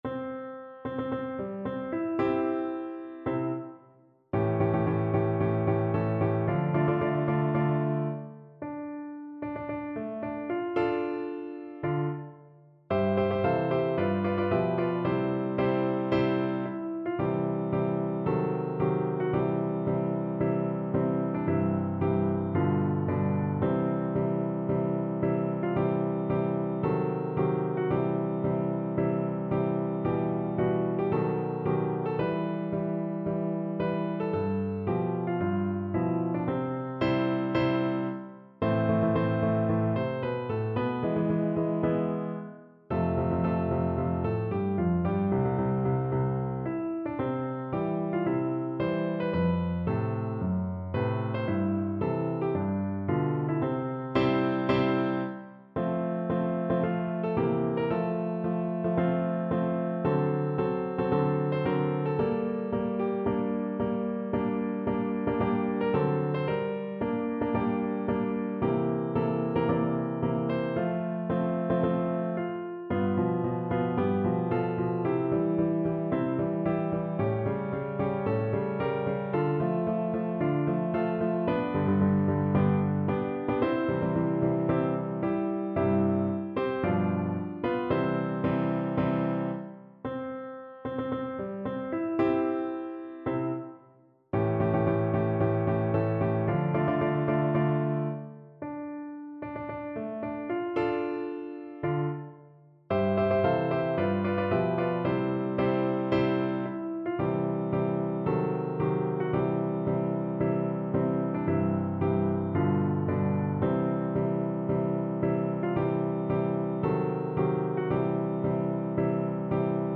Marcial =112
Traditional (View more Traditional Piano Music)